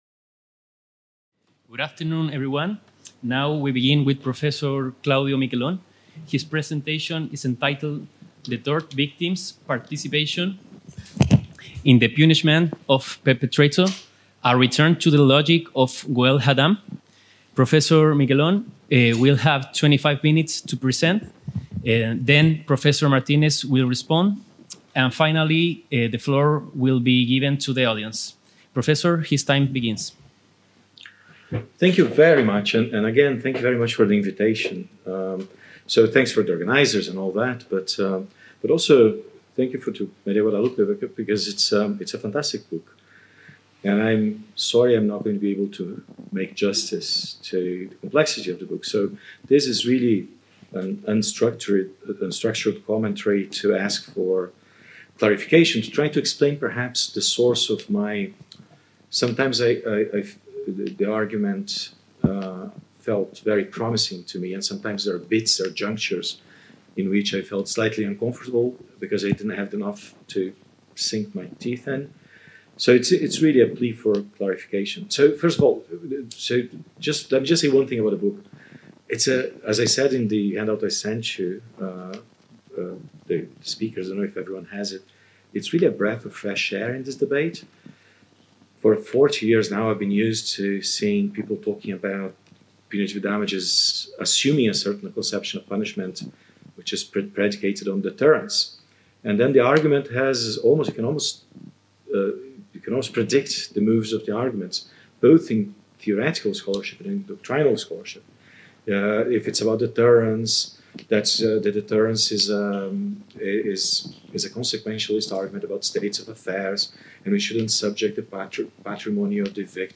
The Chair of Legal Culture at the Faculty of Law of the University of Guadalajara (UdG) is organizing the 9th workshop on the philosophy of private law